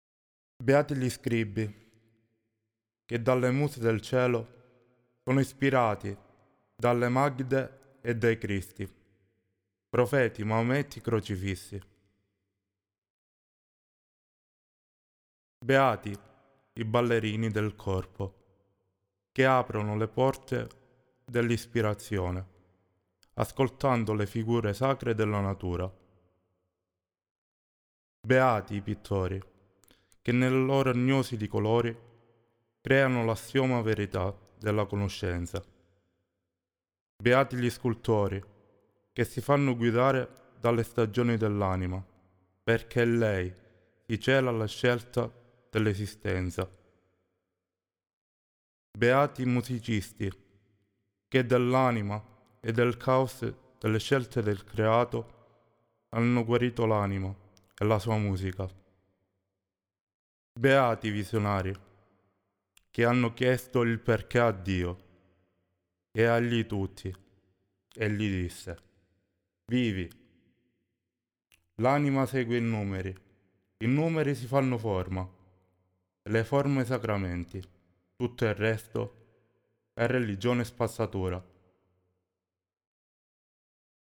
ecco i bianchi (solo voce)